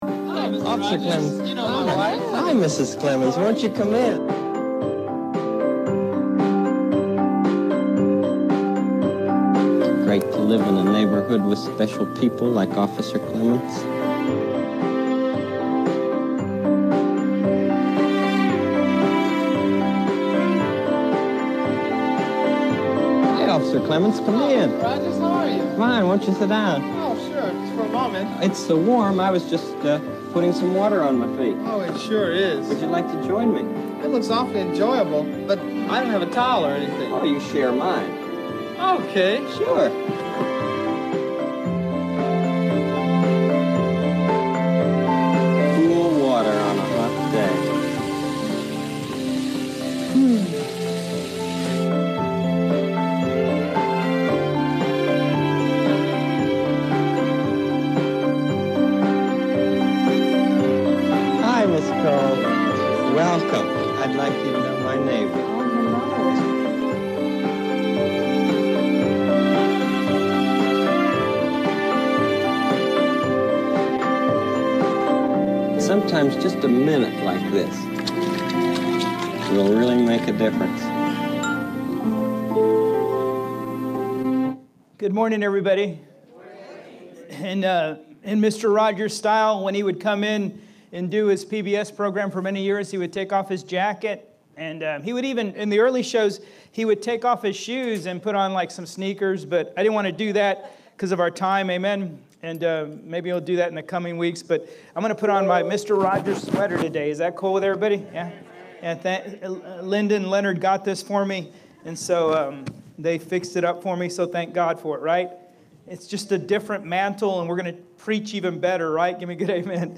Sermons | Living Faith Church